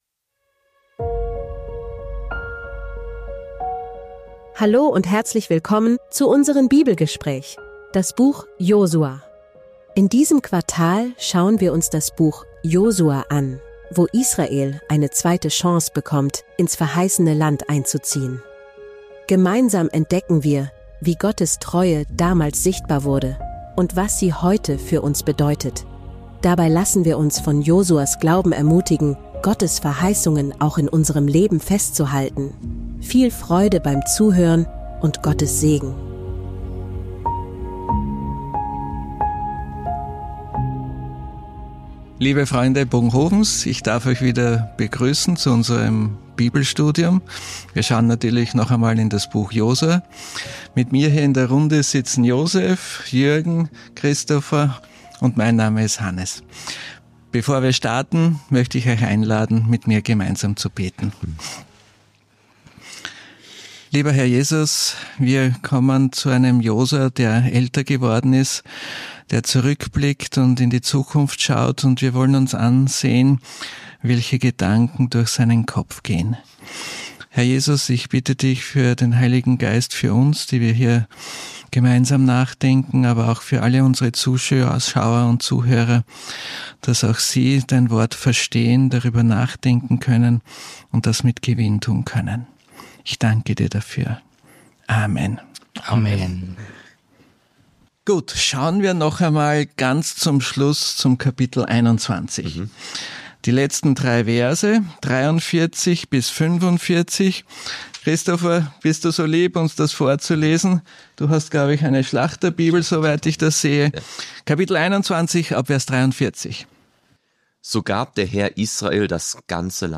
Hier hören Sie das Sabbatschulgespräch aus Bogenhofen zur Weltfeldausgabe der Lektion der Generalkonferenz der Siebenten-Tags-Adventisten